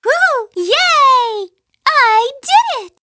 One of Princess Peach's voice clips in Mario Kart 7